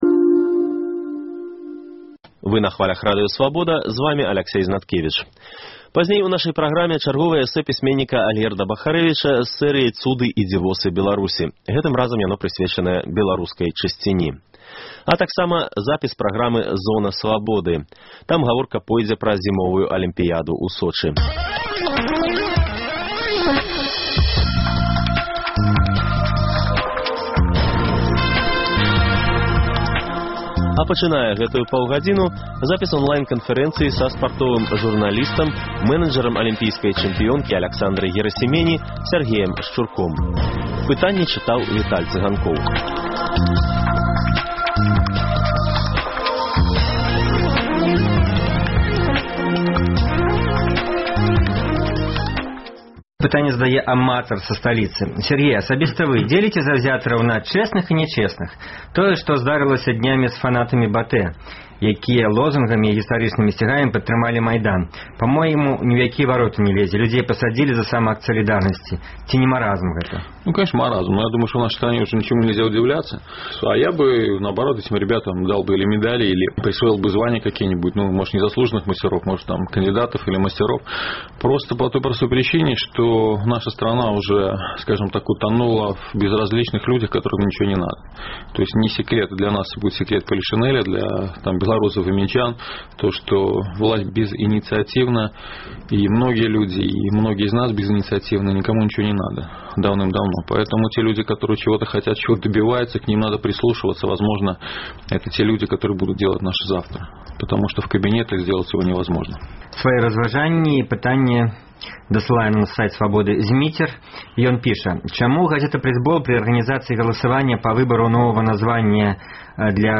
На вашы пытаньні падчас онлайн-канфэрэнцыі адказаў вядомы спартовы журналіст